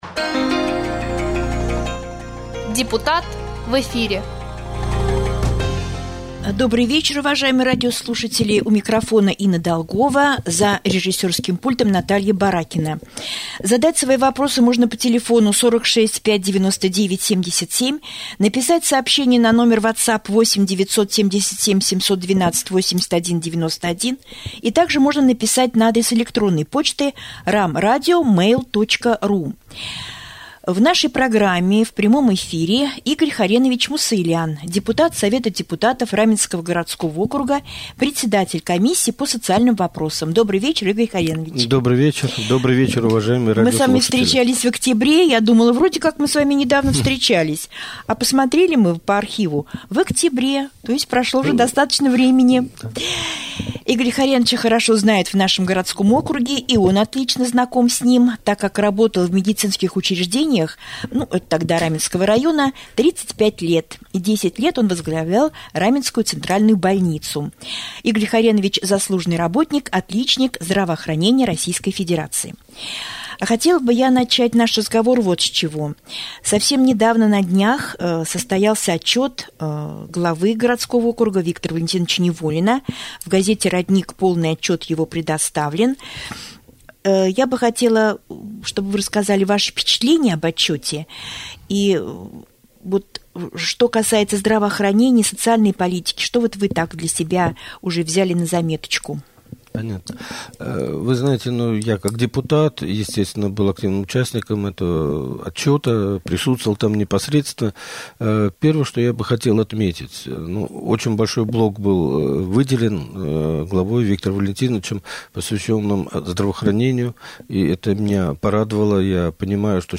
Гостем студии Раменского радио стал Игорь Хоренович Мусаелян, депутат Совета депутатов Раменского городского округа.